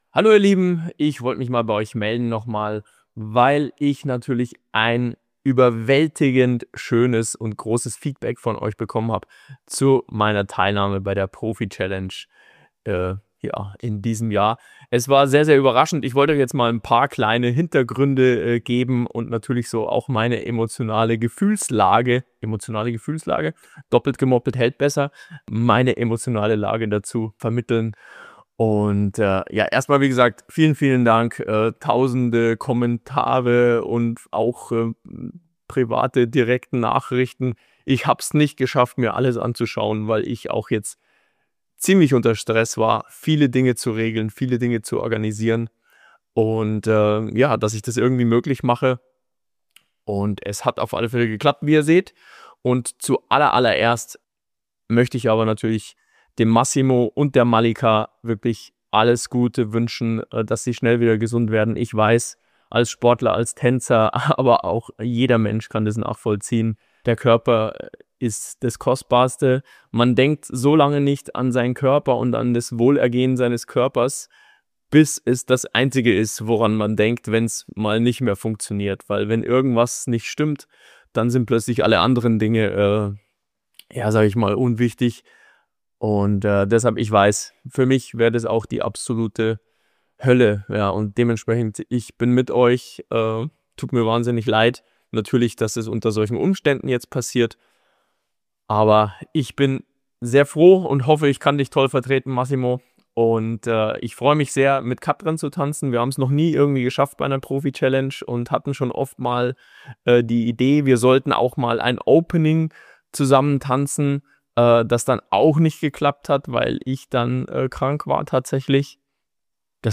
In diesem kurzen Video spricht Christian Polanc über die bevorstehende Profi-Challenge und das große Finale von „Let's Dance“ 2025.